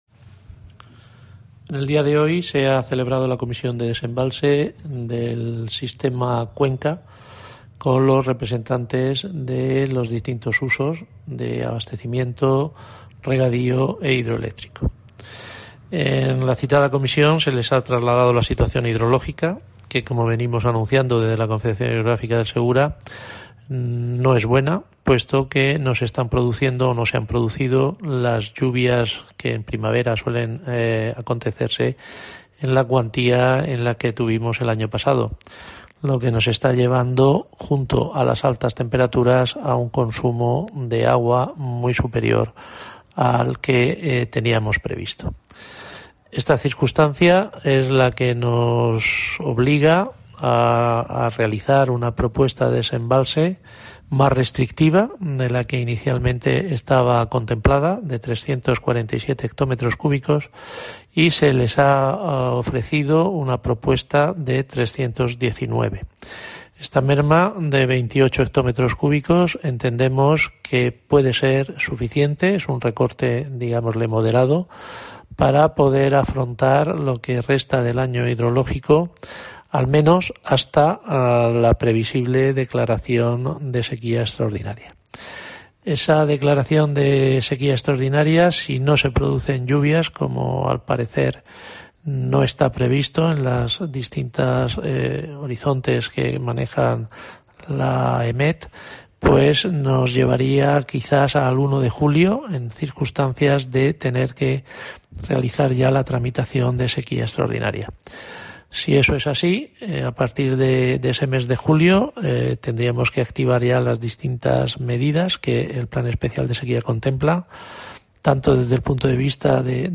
Mario Urrea, presidente de la Confederación Hidrográfica del Segura